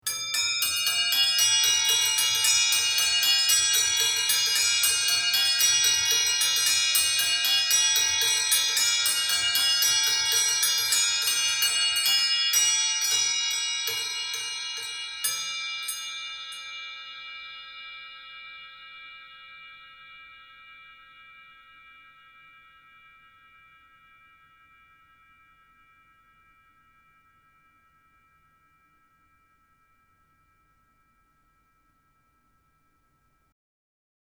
Cymbelstern mit 8 gestimmten Schalenglocken
8 Schalenglocken traditionell aus Glockenbronze gegossen.
Bewegung über Massivholzwelle durch hölzerne Klöppel mit MS-Einlage.
Es findet keine exakte Stimmung wie bei einem Glockenspiel statt, sondern nur eine annähernde.